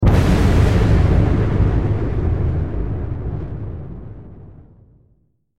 دانلود آهنگ تانک 4 از افکت صوتی حمل و نقل
جلوه های صوتی
دانلود صدای تانک 4 از ساعد نیوز با لینک مستقیم و کیفیت بالا